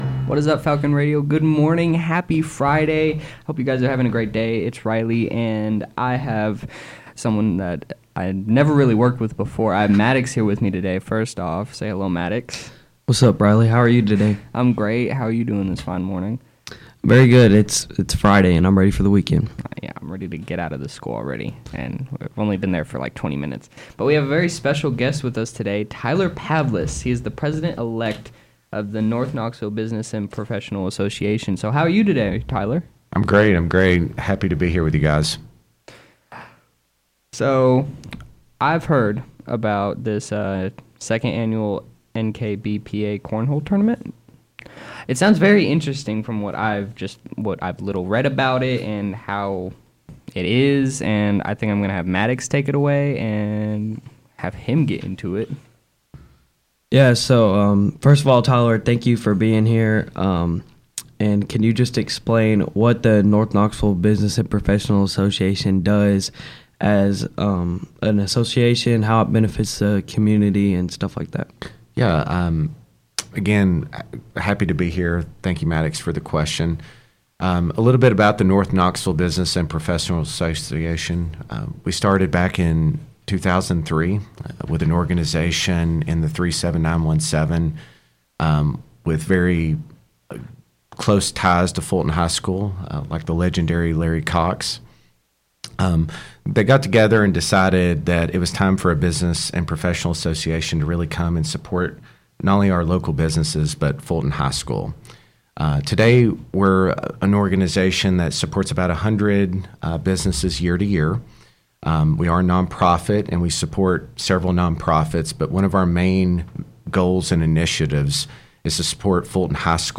NKBPA Interview 4/19/24